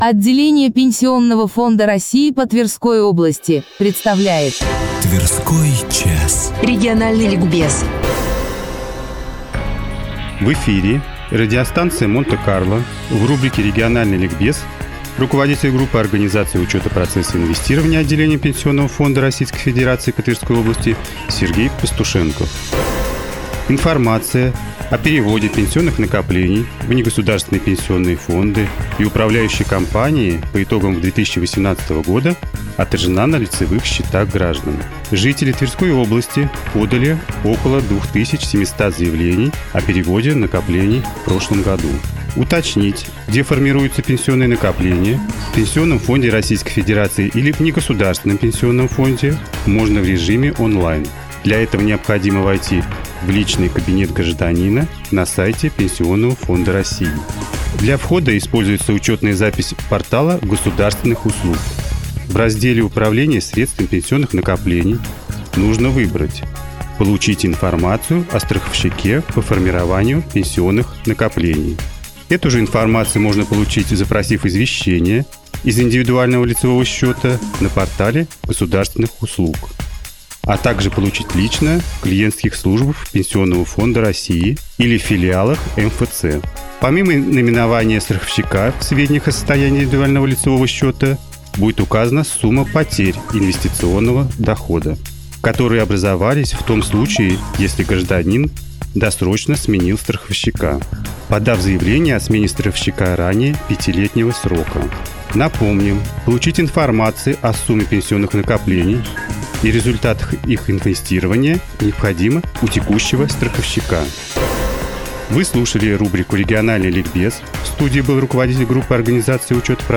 Выступление в эфире радио "Монте-Карло"